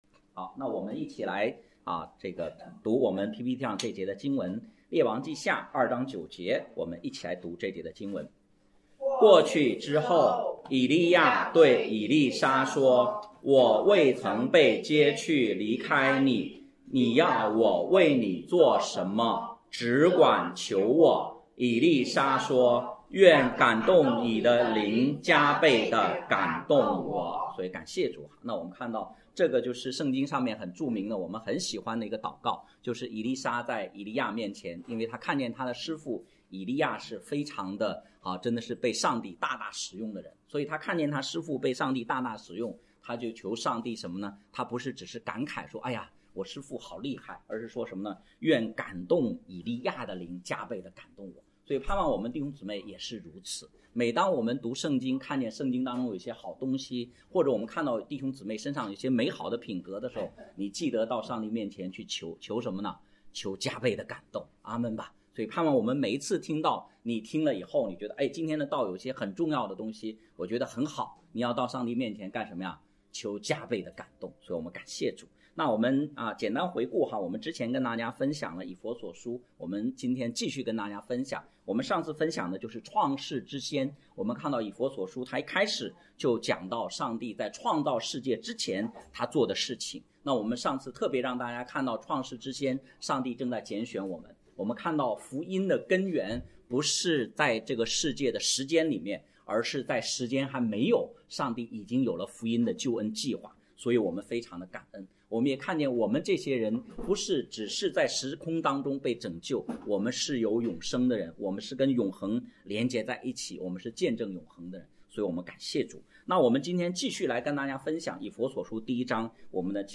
系列查经讲道